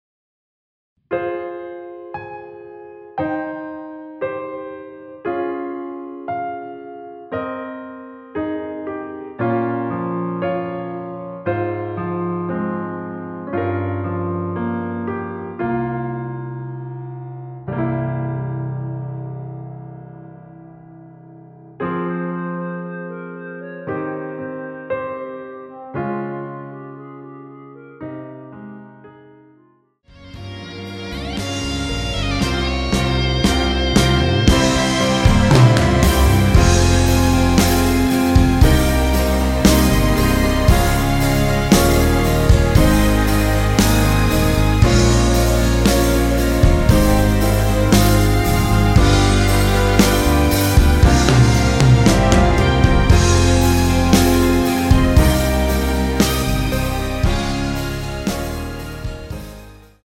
원키에서(-2)내린 멜로디 포함된 MR입니다.
Fm
앞부분30초, 뒷부분30초씩 편집해서 올려 드리고 있습니다.
중간에 음이 끈어지고 다시 나오는 이유는